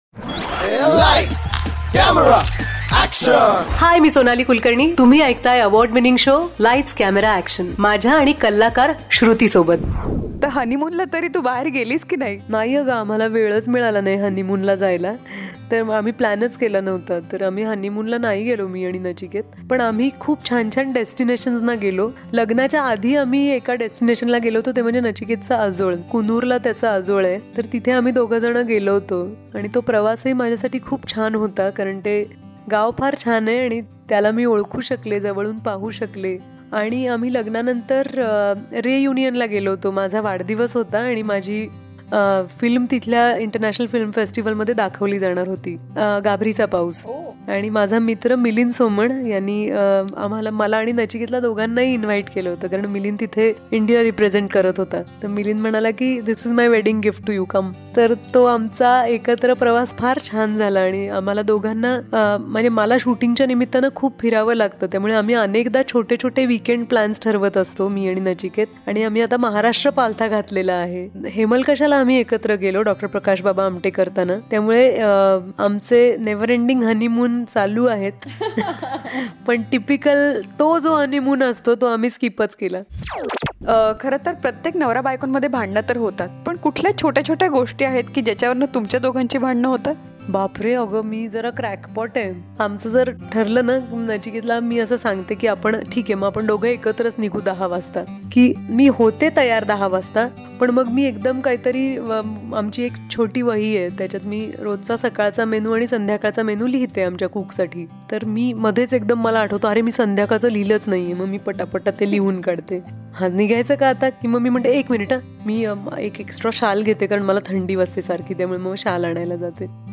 CONVERSATION WITH WELL KNOWN ACTRESS SENIOR SONALI KULKARNI PART 8